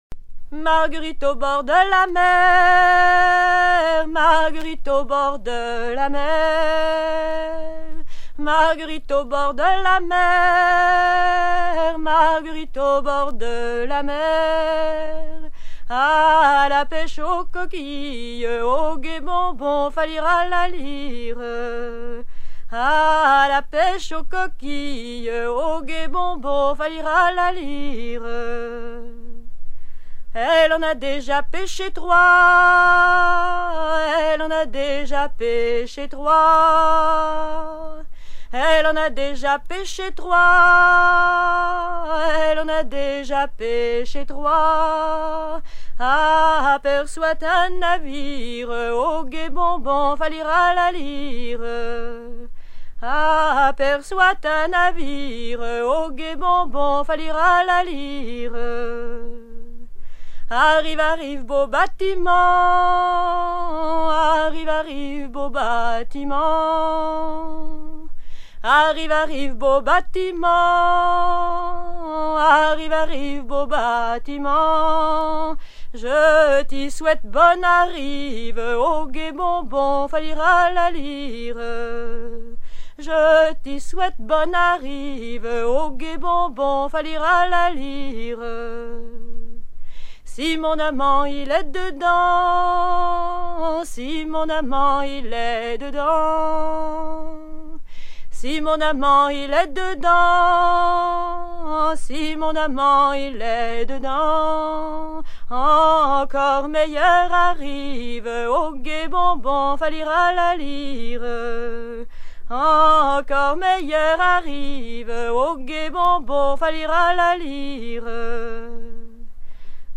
Version recueillie en 1976
Genre laisse
Pièce musicale éditée